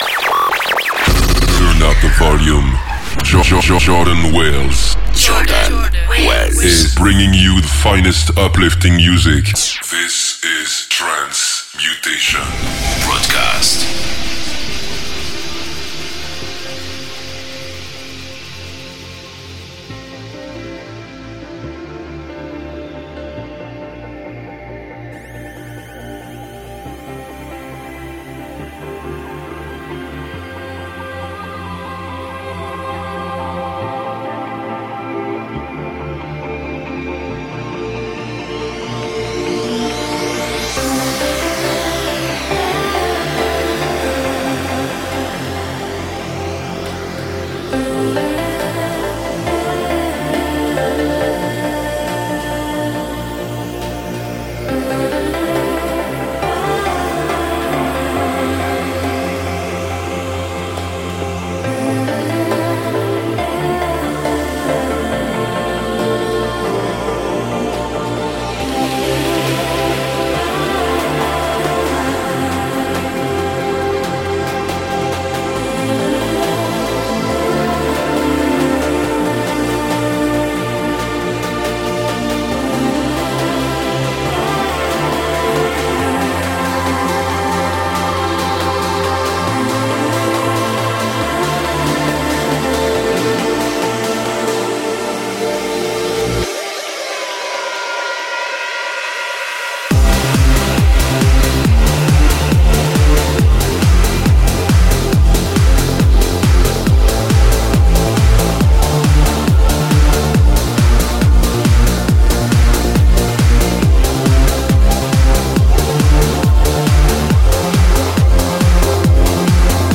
Uplifting trance, what else should we say